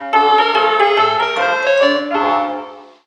jackpot music